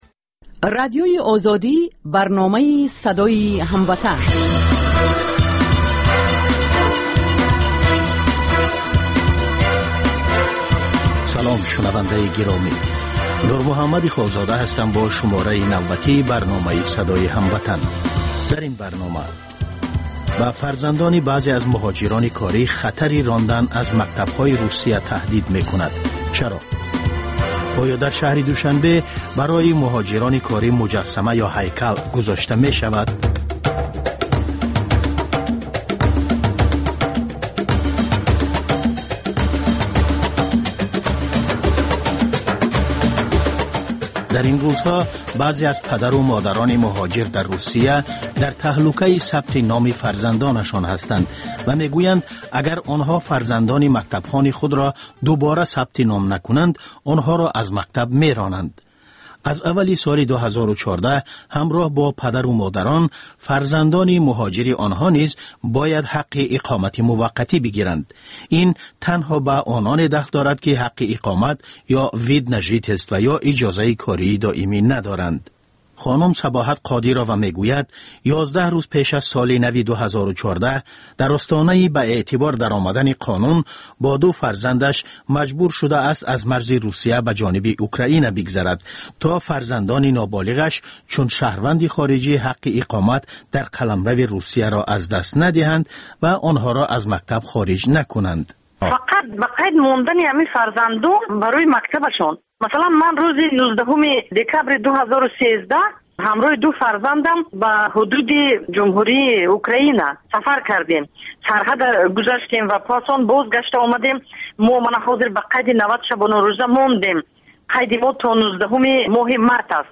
Тоҷикон дар кишварҳои дигар чӣ гуна зиндагӣ мекунанд, намунаҳои комёб ва нобарори муҳоҷирон дар мамолики дигар, мусоҳиба бо одамони наҷиб.